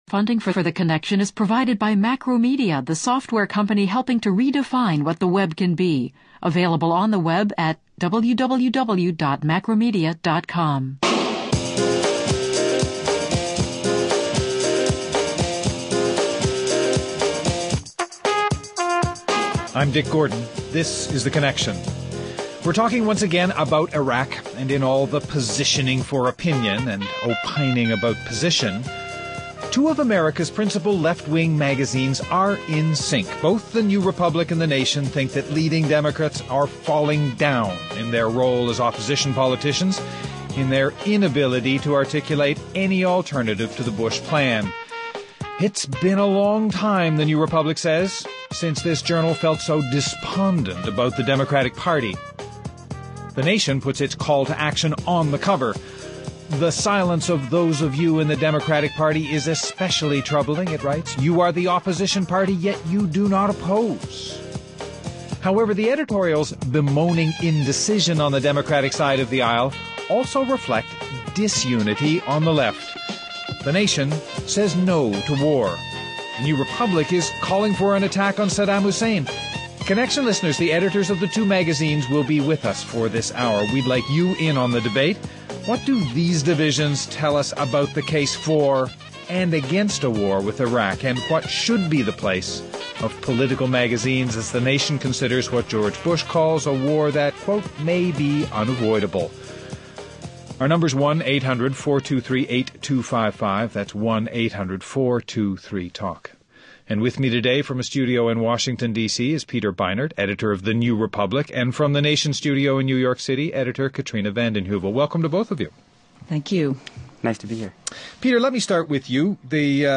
Guests: Katrina vanden Heuvel, editor of the Nation Peter Beinart, editor of the New Republic.